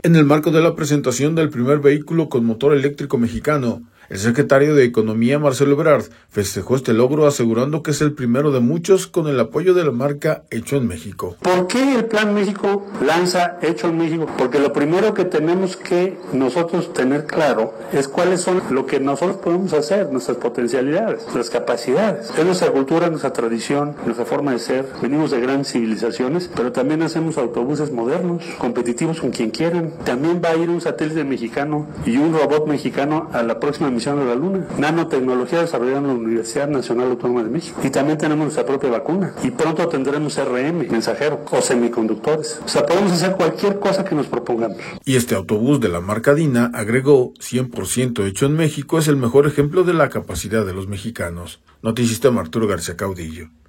En el marco de la presentación del primer vehículo con motor eléctrico mexicano, el secretario de Economía, Marcelo Ebrard, festejó este logro asegurando que es el primero de muchos con el apoyo de la marca Hecho en México.